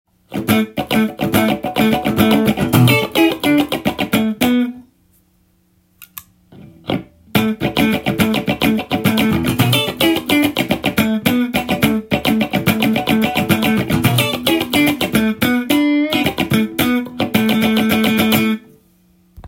コンプレッサーは音量と音の伸びやピッキングのアタック音などを
試しに弾いてみました。
スイッチをオンすると音量が一定に保たれるので